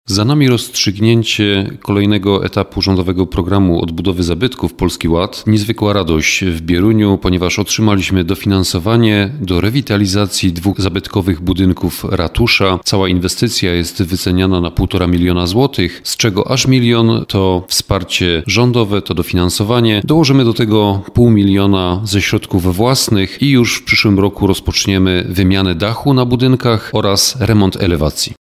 – Planowane roboty budowlane będą obejmować wymianę dachu oraz renowację elewacji. Całkowita przewidywana wartość inwestycji to 1,5 miliona złotych, z czego 1 milion to dofinansowanie Rządowego Programu Odbudowy Zabytków – mówi Krystian Grzesica, burmistrz Bierunia.